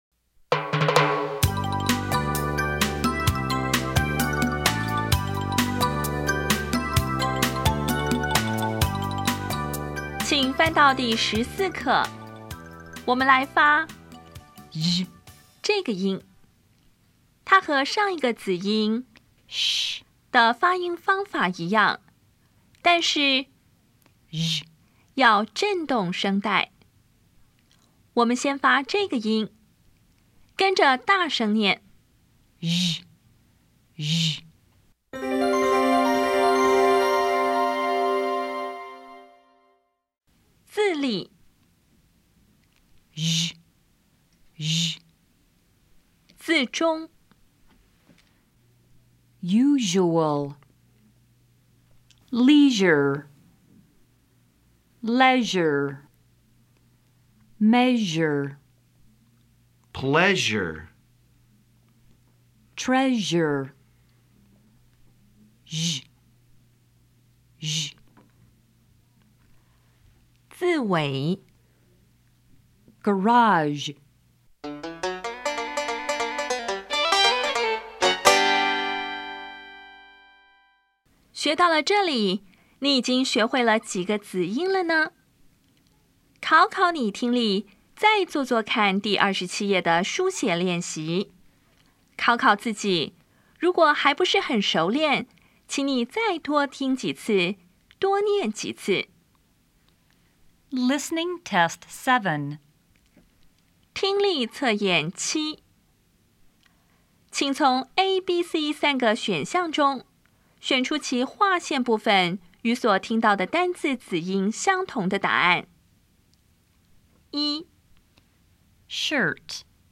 当前位置：Home 英语教材 KK 音标发音 子音部分-2: 有声子音 [ʒ]
音标讲解第十四课
[ˋjuʒʊəl]
[gəˋrɑʒ]
Listening Test 7